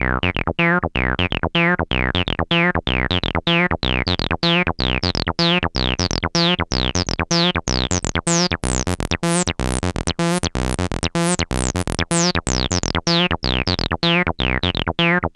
cch_acid_loop_sawtooth_125_Db.wav